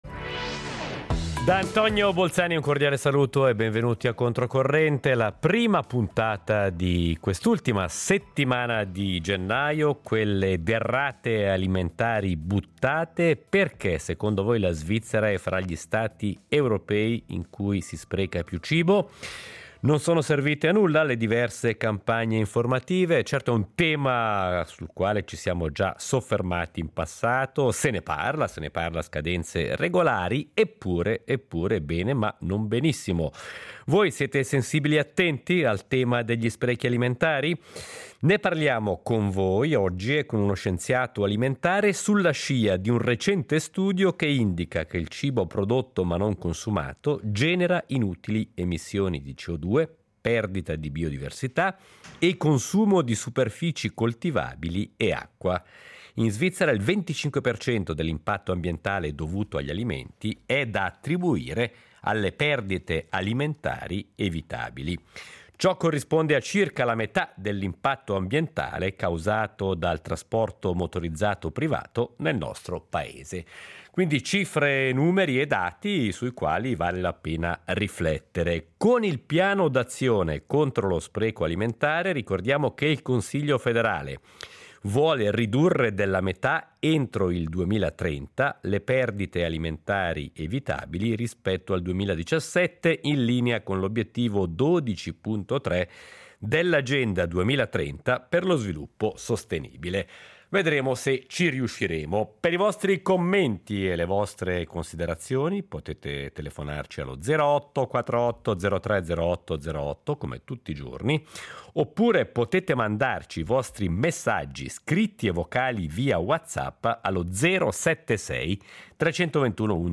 Ne parliamo con voi e con uno scienziato alimentare, sulla scia di un recente studio che indica che il cibo prodotto ma non consumato genera inutili emissioni di CO2, perdita di biodiversità e consumo di superfici coltivabili e acqua. In Svizzera il 25 per cento dell’impatto ambientale dovuto agli alimenti è da attribuire alle perdite alimentari evitabili.